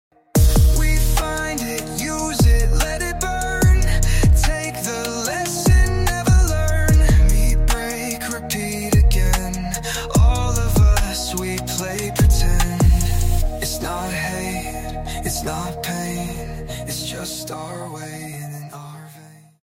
dark and introspective song